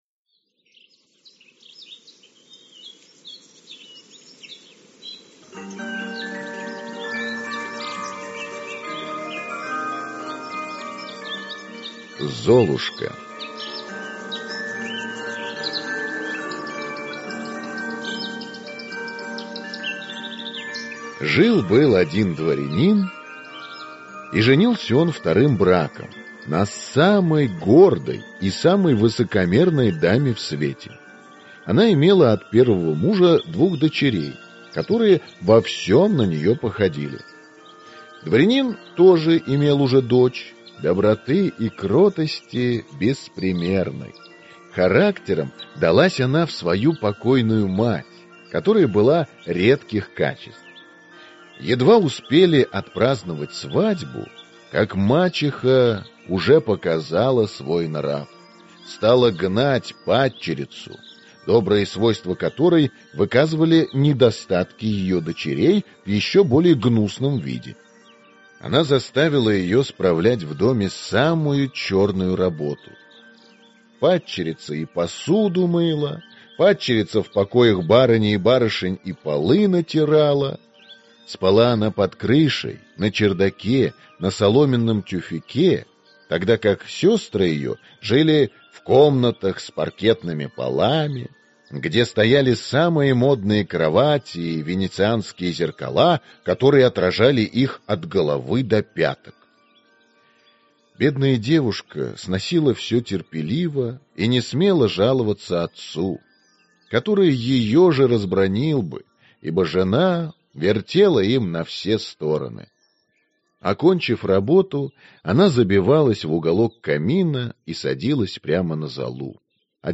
Аудиокнига Золушка - Перро Шарль - Скачать книгу, слушать онлайн